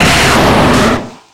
Cri de Rhinoféros dans Pokémon X et Y.